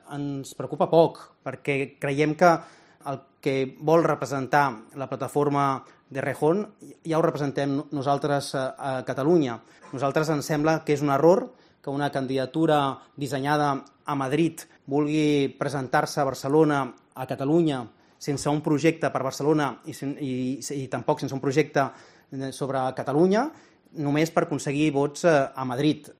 Declaraciones del cabeza de lista de En Comú Podem, Jaume Asens
Asens ha dicho en rueda de prensa que la formación "no tiene proyecto para Catalunya ni para su capital".